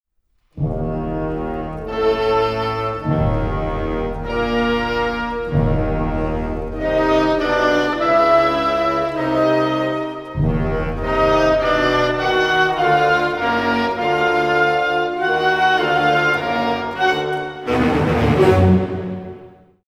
Trascrizione per orchestra di Maurice Ravel